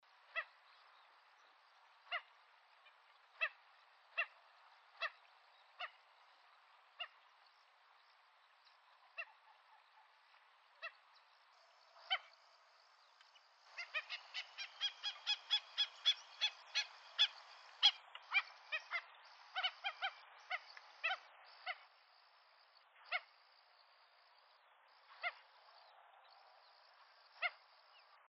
Black-necked Stilt (Himantopus mexicanus)
Life Stage: Adult
Location or protected area: Dique Los Alisos
Condition: Wild
Certainty: Photographed, Recorded vocal